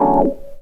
HOUSE 5-L.wav